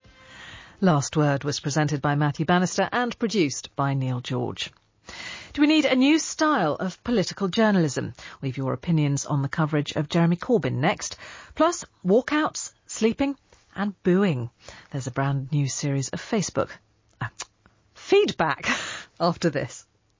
I love the exasperated cluck too.